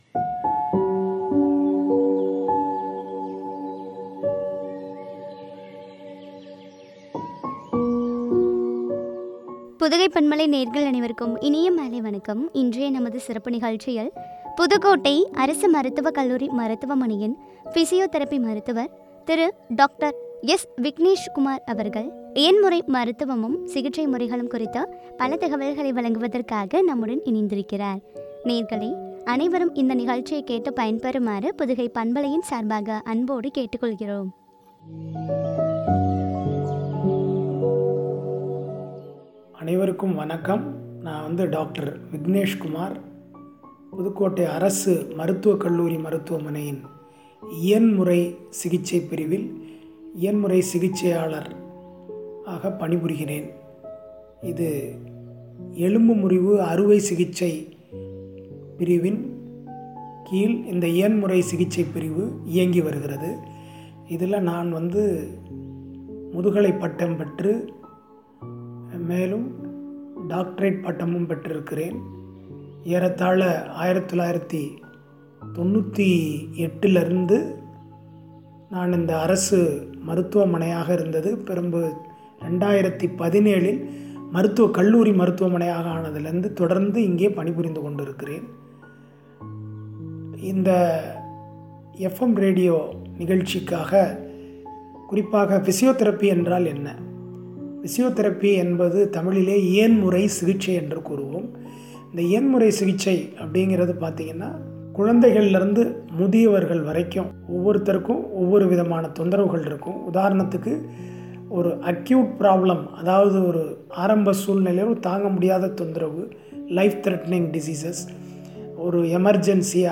சிகிச்சை முறைகளும் பற்றிய உரையாடல்.